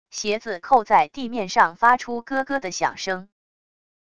鞋子扣在地面上发出咯咯的响声wav音频